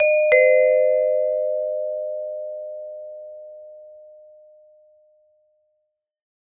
doorbell_alarm.mp3